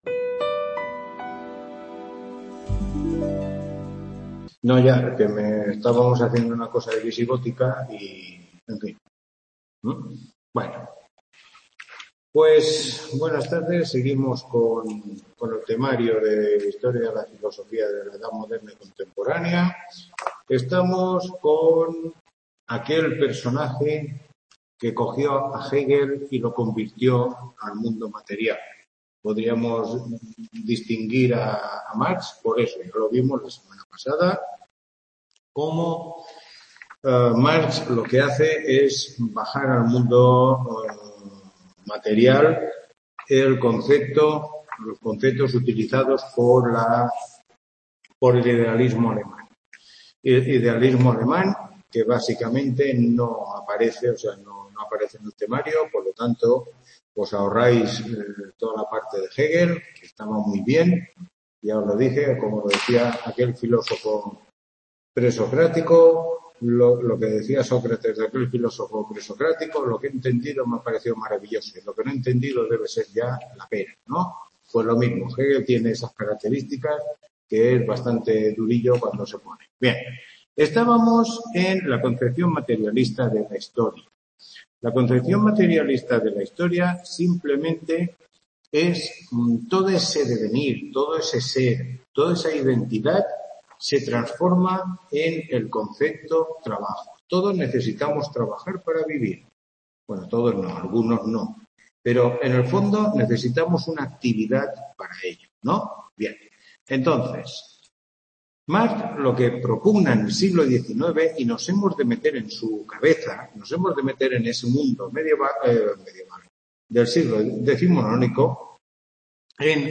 Tutoría 5